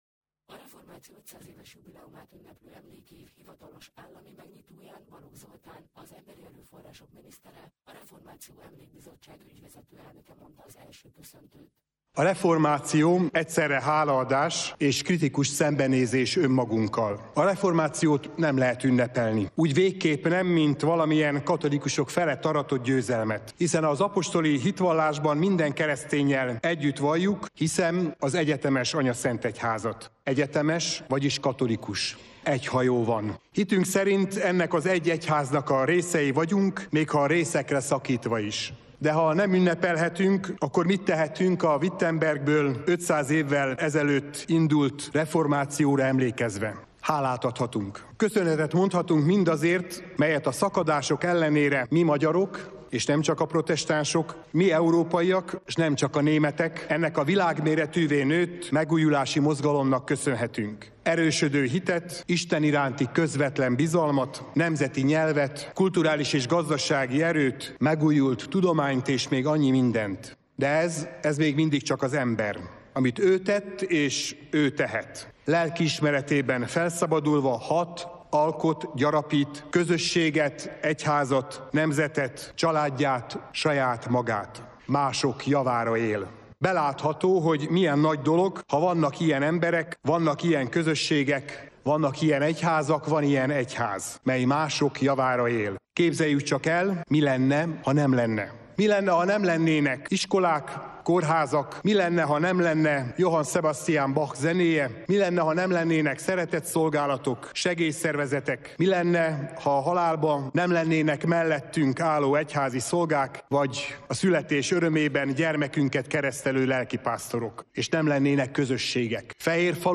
A Művészetek Palotájában tartott, január 6-i ünnepélyes alkalmon részt vettek a protestáns egyházak és az állam képviselői is. A szervezők az „Egy asztal körül” címet adták a megnyitónak, ezzel is szimbolizálva a reformáció magyarságra gyakorolt hatását.